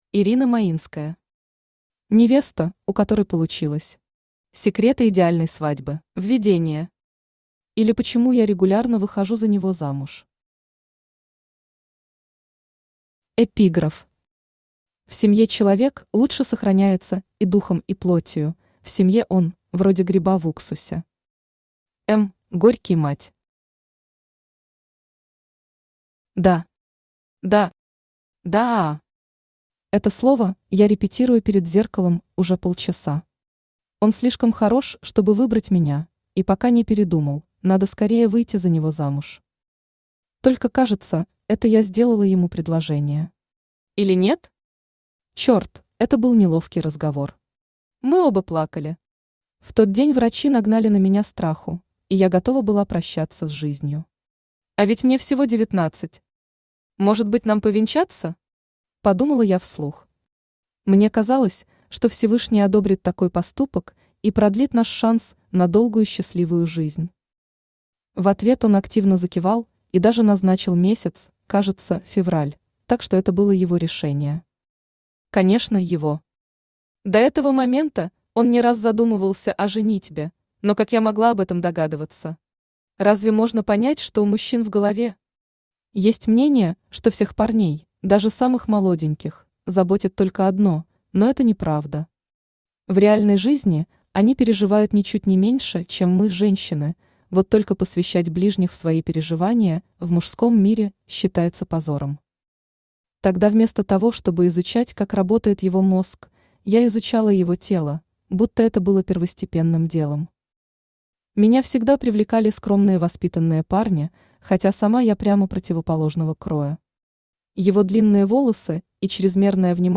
Аудиокнига Невеста, у которой получилось. Секреты идеальной свадьбы | Библиотека аудиокниг
Секреты идеальной свадьбы Автор Ирина Маинская Читает аудиокнигу Авточтец ЛитРес.